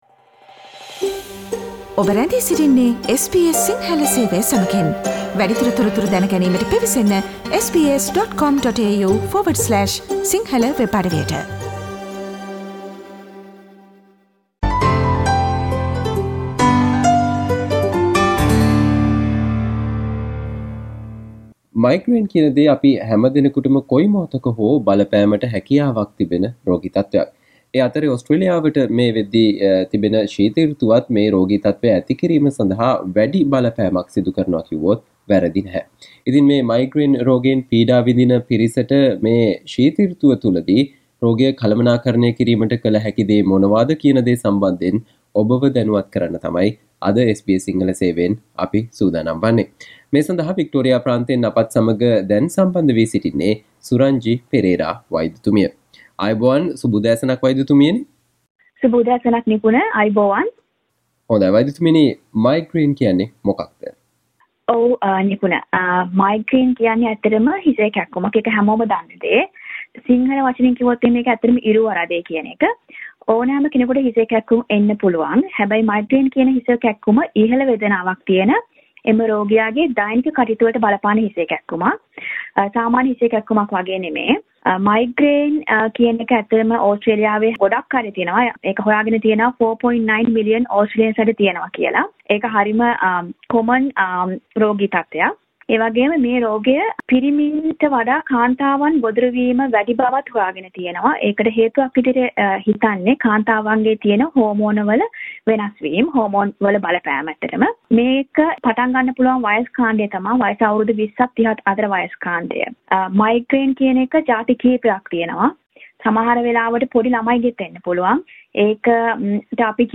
Listen to the SBS Sinhala discussion on Things to be aware of when dealing with Migraine this winter
SBS Sinhala Interview